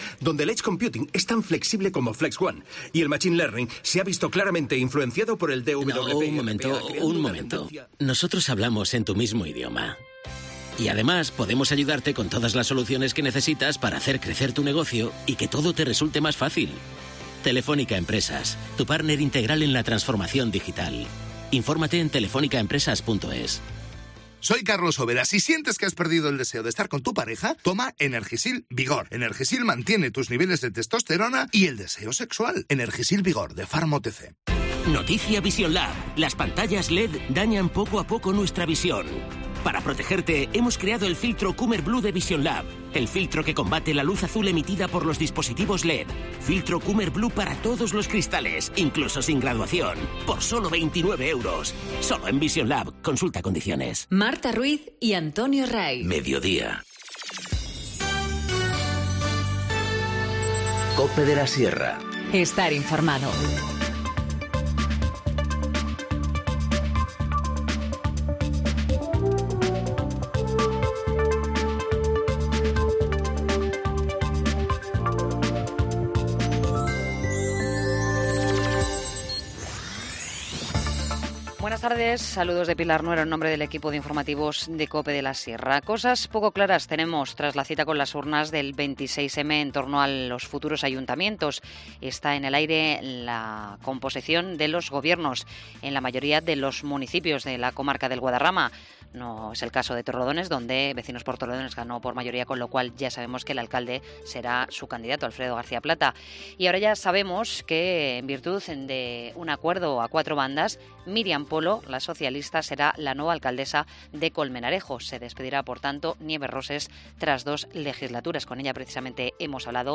Informativo Mediodía 3 junio 14:20h